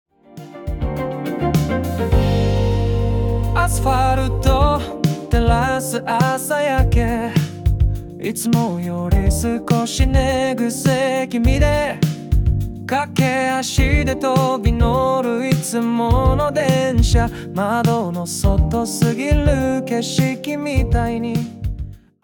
City Pop
▼SUNOで作成した楽曲